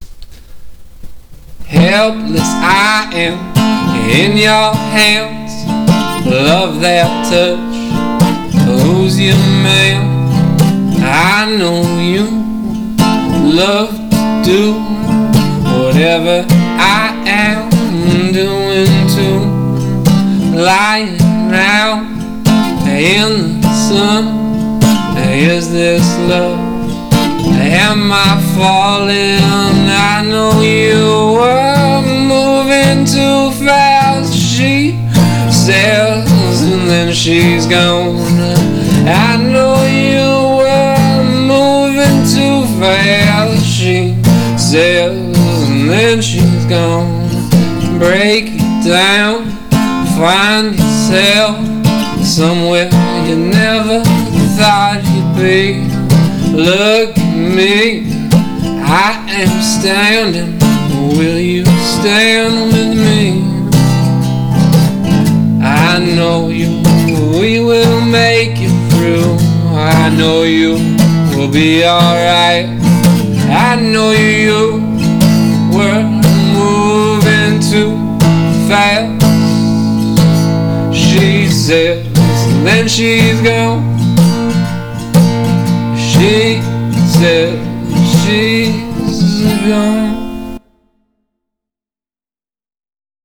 New Boss Recorder And Lacquer-less Guitar (sexy digital clipping)